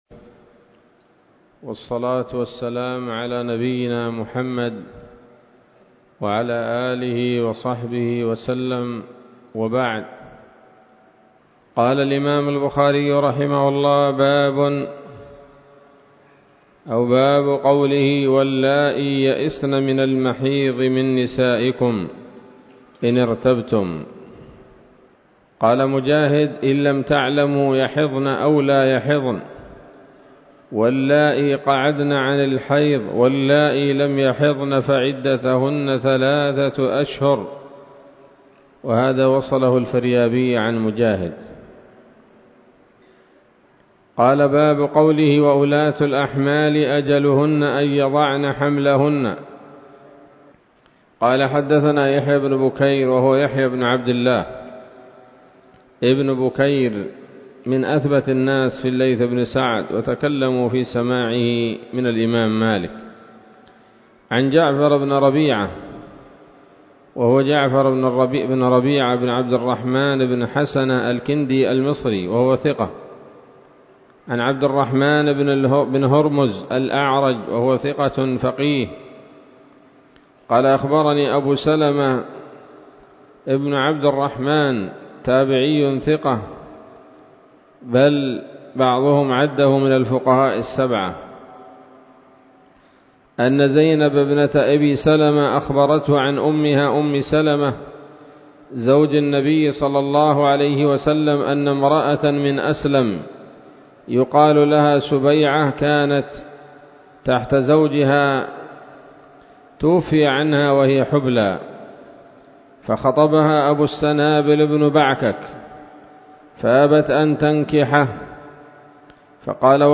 الدرس التاسع والعشرون من كتاب الطلاق من صحيح الإمام البخاري